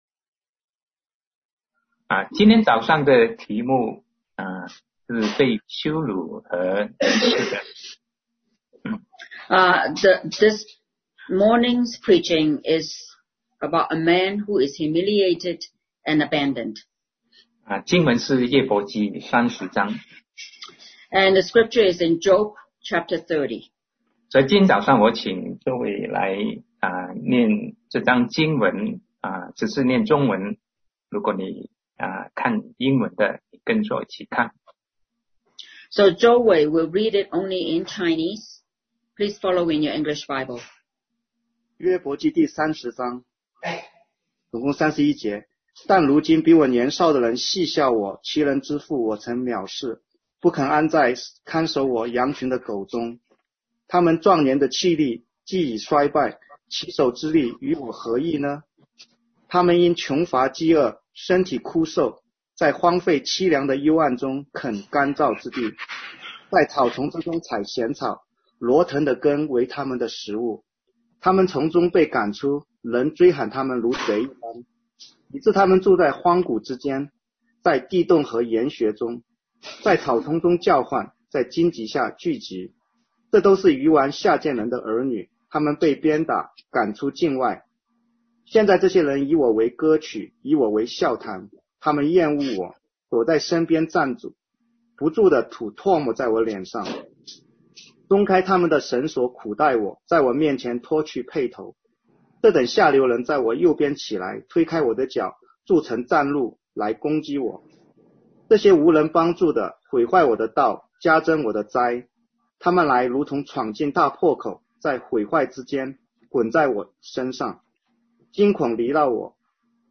Sermon 2020-06-21 A Man Who is Humiliated and Abandoned